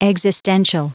Prononciation du mot existential en anglais (fichier audio)